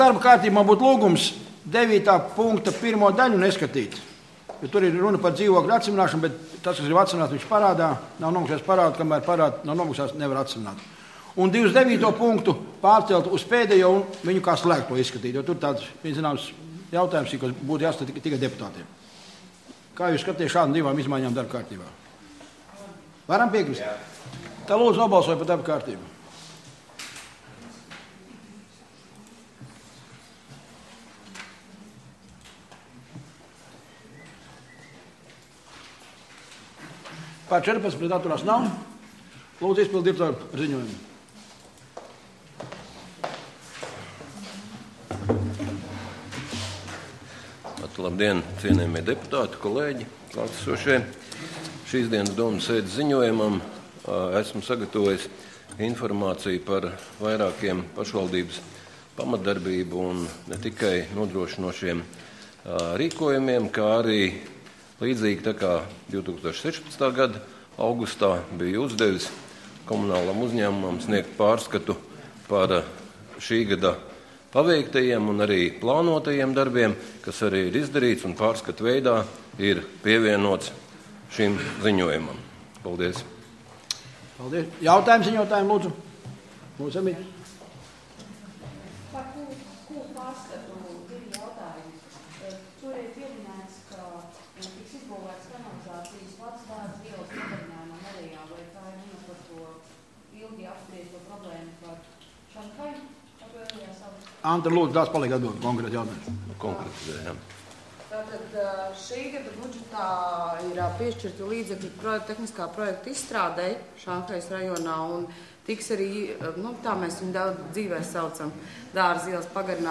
Domes sēde Nr. 14